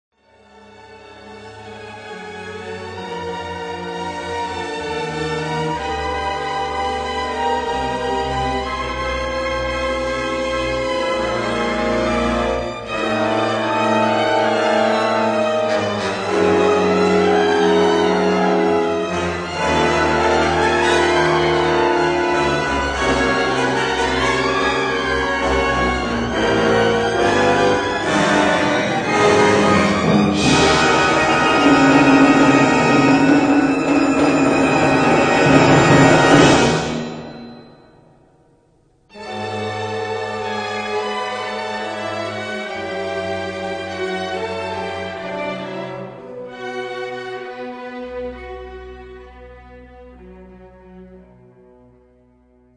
for Symphonic Orchestra